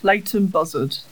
Leighton Buzzard (/ˈltən ˈbʌzərd/